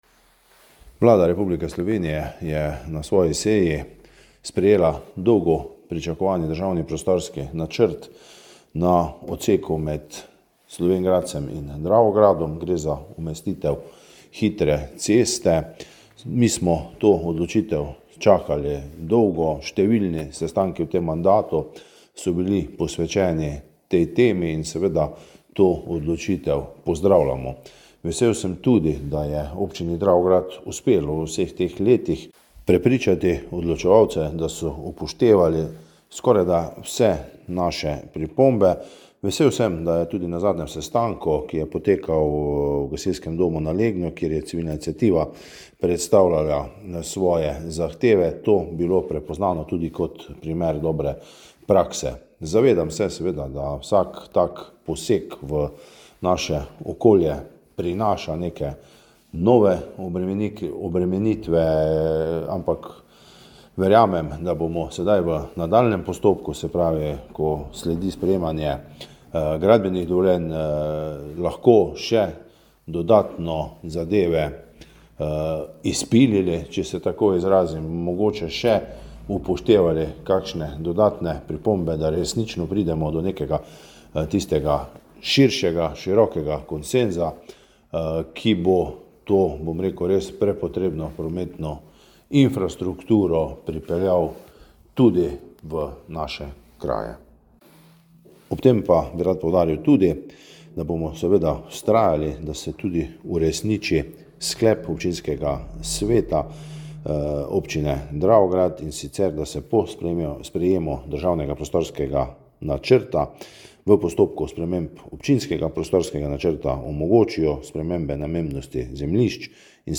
Dravograjski župan Anton Preksavec: